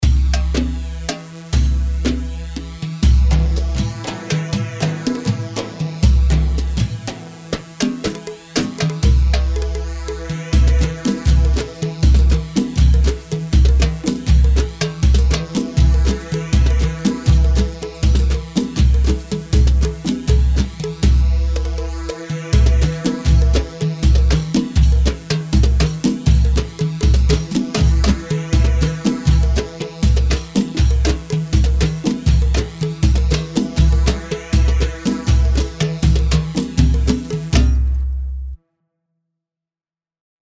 Atba 3/4 120 عتبة